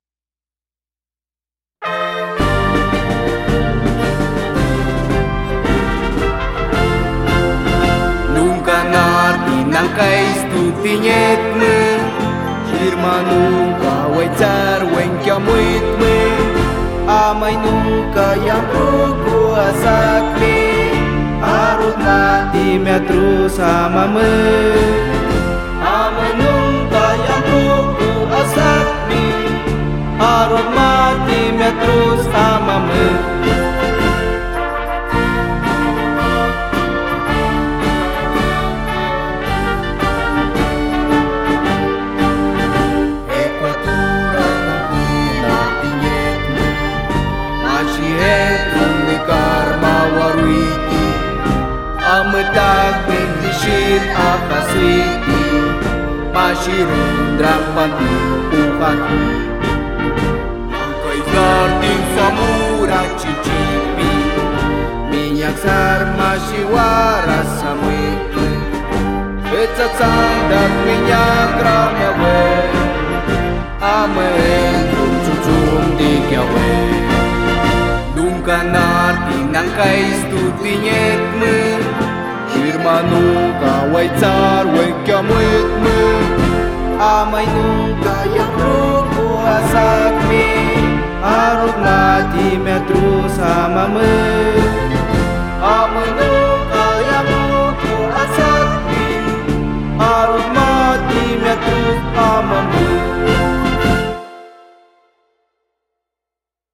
Himno del Cantón Nangaritza